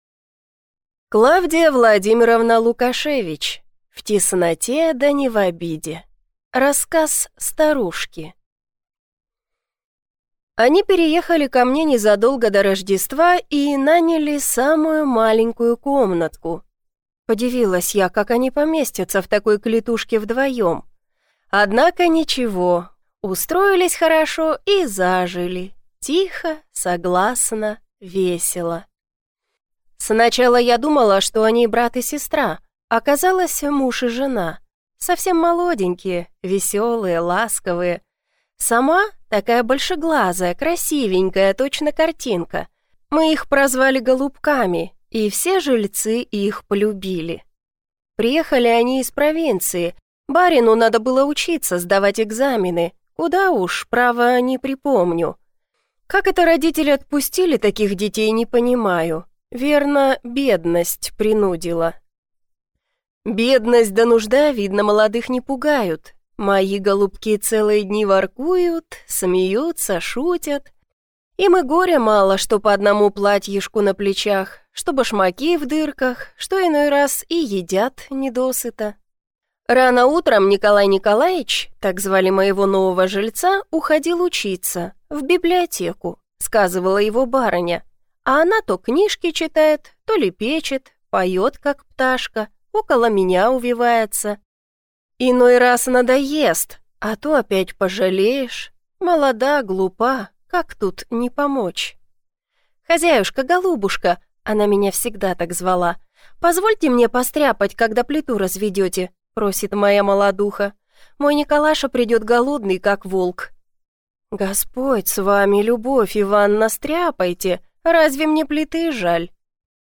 Аудиокнига В тесноте, да не в обиде | Библиотека аудиокниг